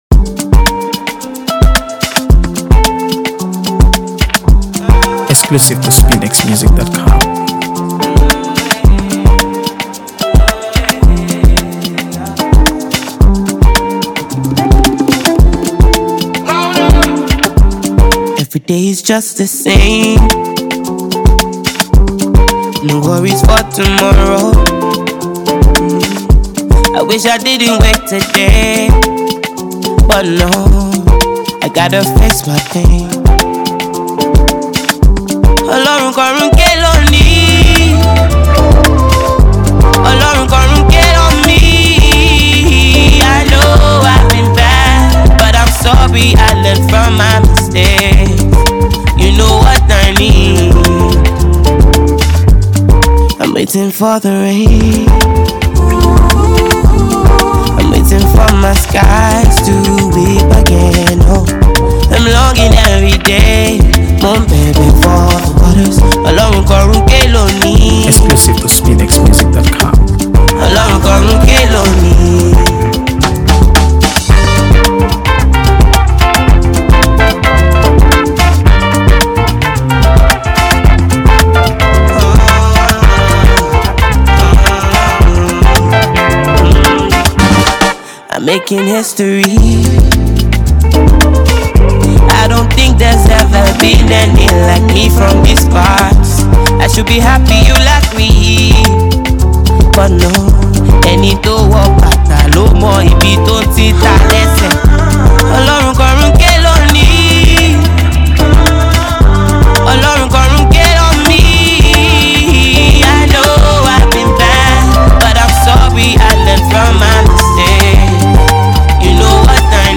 AfroBeats | AfroBeats songs
Phenomenally talented Nigerian singer